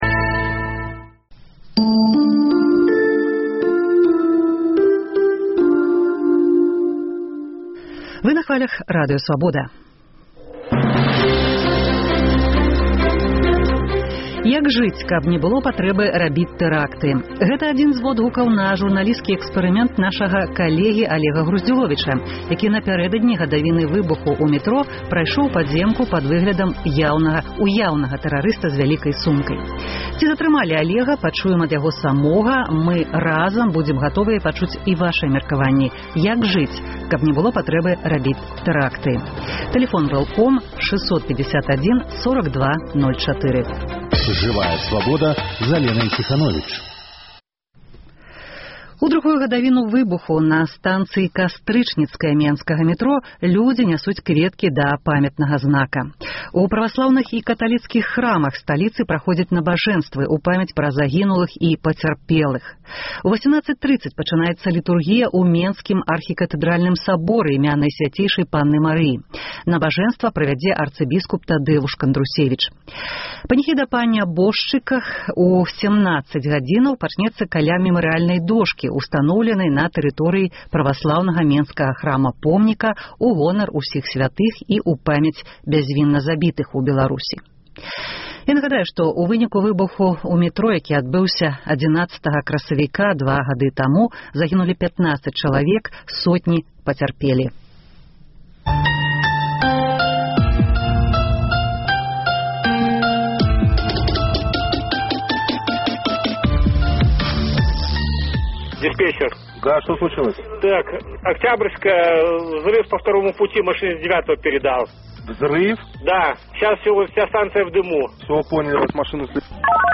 Як жыць, каб не было патрэбы рабіць тэракты? Гутарка